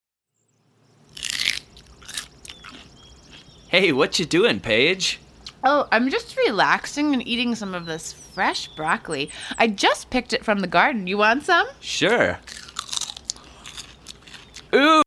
Cheerful songs jubilantly performed